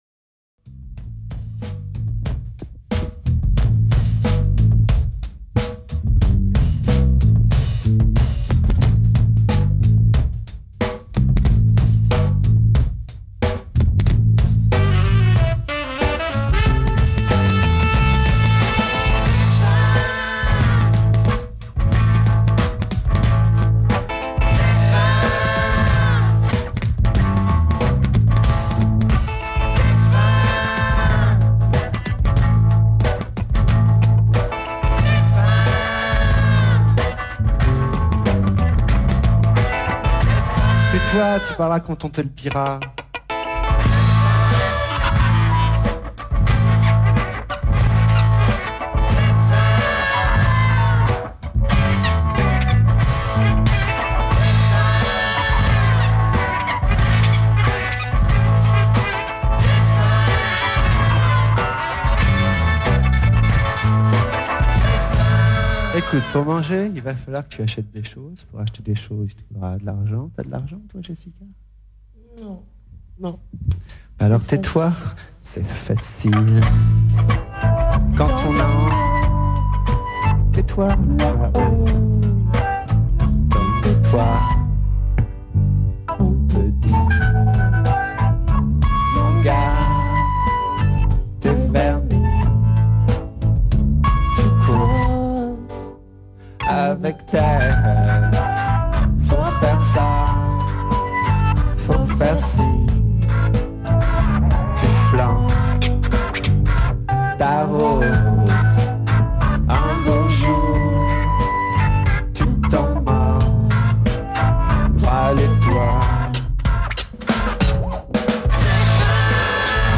enregistrée le 12/03/2001  au Studio 105